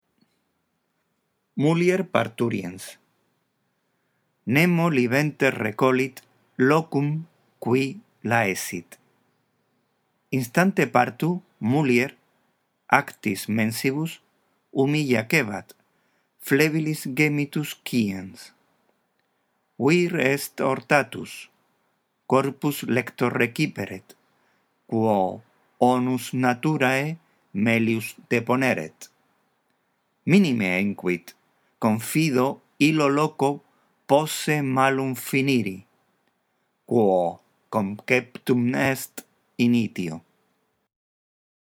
La audición de este archivo te ayudará en la práctica de la lectura del latín